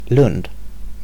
Lund (/lʊnd/, US also /lʌnd/ LU(U)ND;[2][3][4][5] Swedish: [ˈlɵnːd]